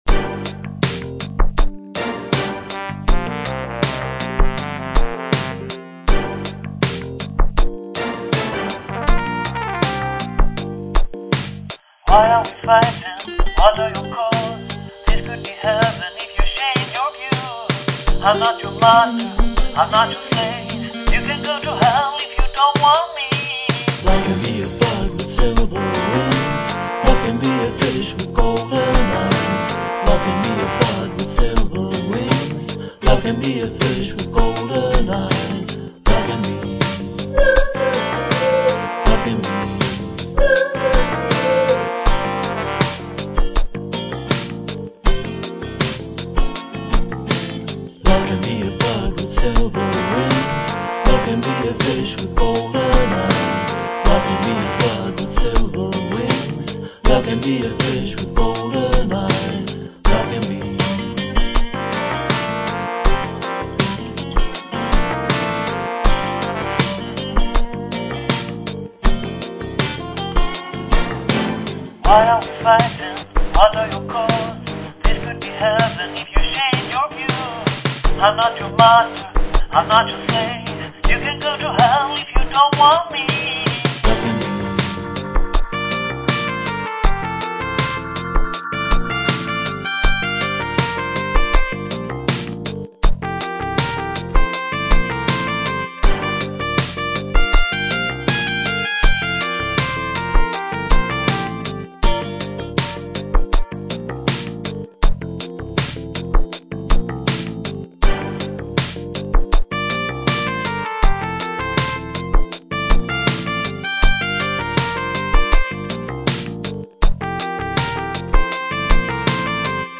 All songs are recorded and mixed in their home studios.